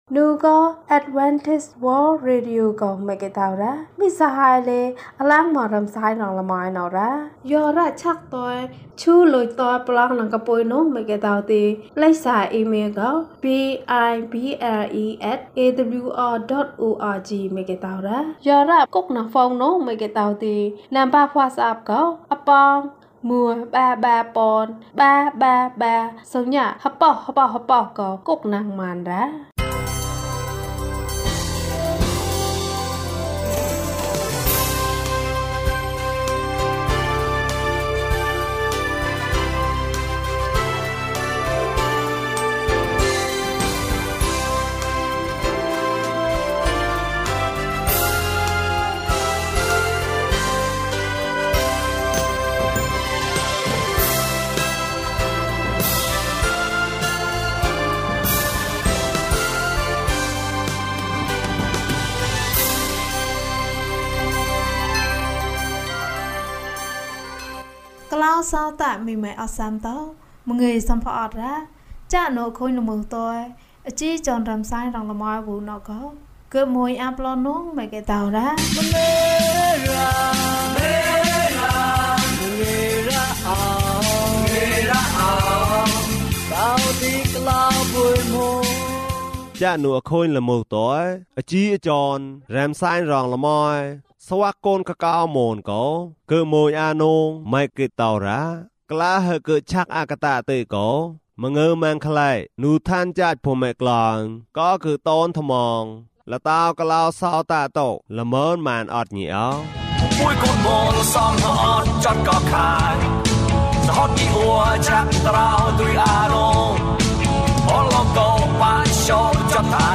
ခရစ်တော်ထံသို့ ခြေလှမ်း။၀၉ ကျန်းမာခြင်းအကြောင်းအရာ။ ဓမ္မသီချင်း။ တရားဒေသနာ။